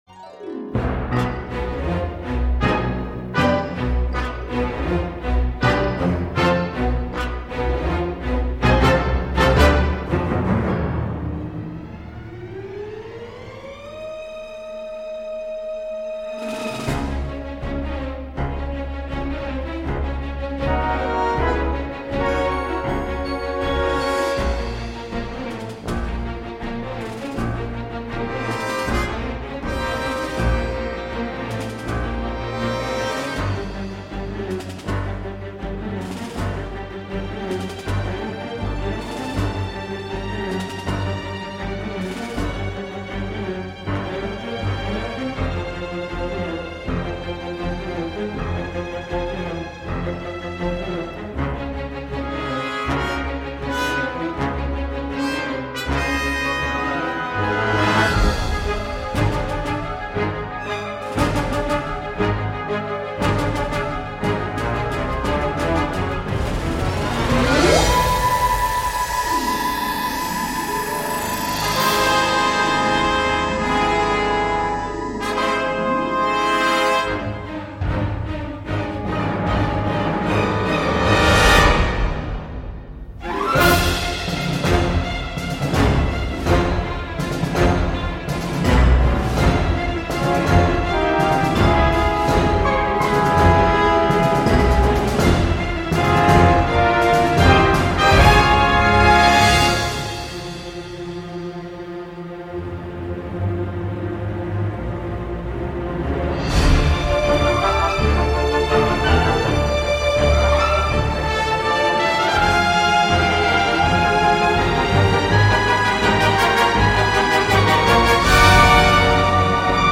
ondes Martenot comprises